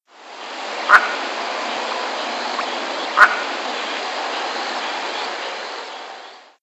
Callonetta leucophrys - Pato de collar
patodecollar.wav